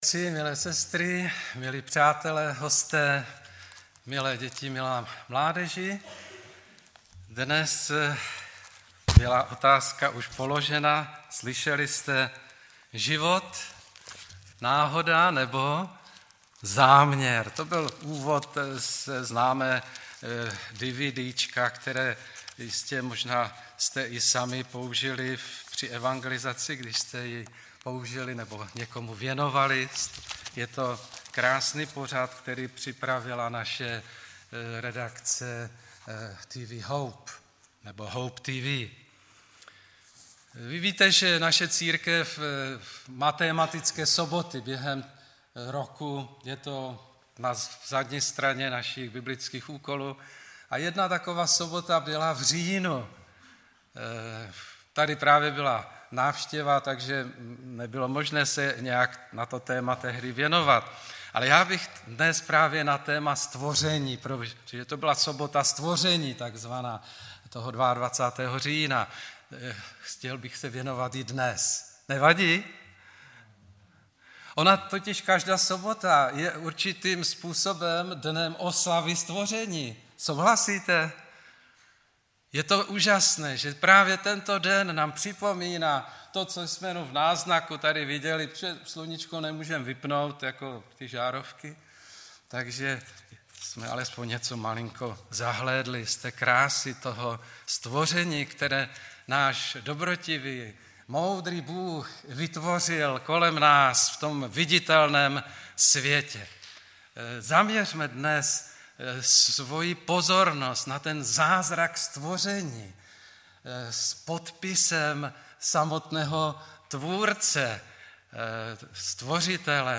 3.12.2016 v 18:06 do rubriky Kázání .